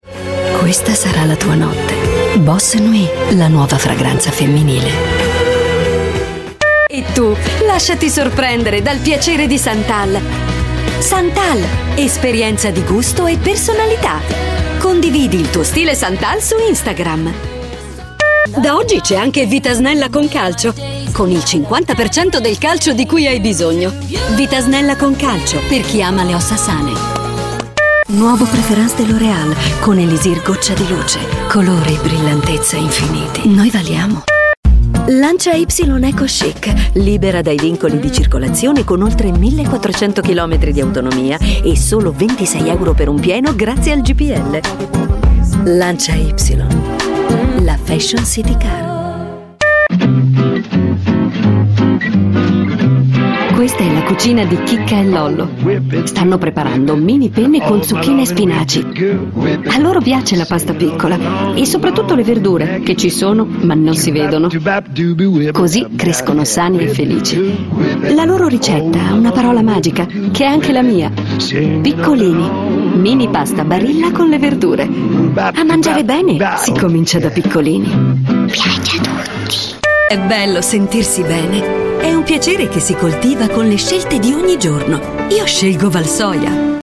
Italian Voice Over